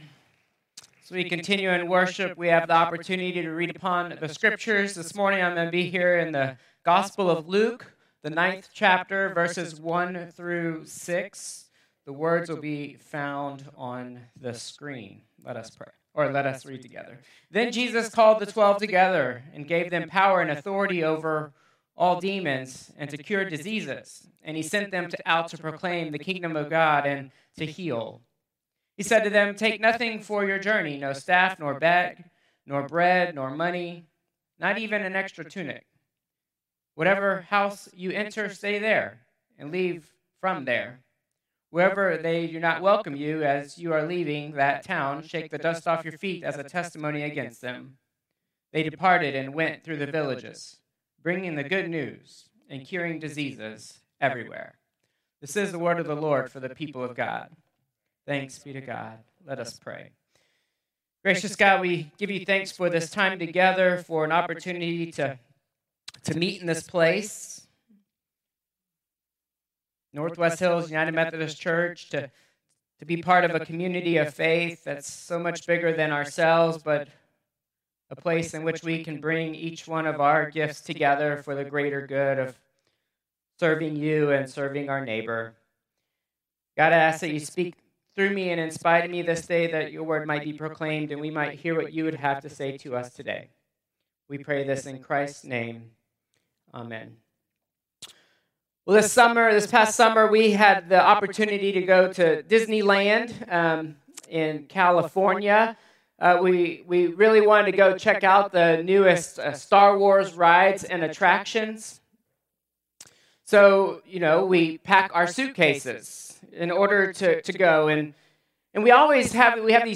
Contemporary Service 11/2/2025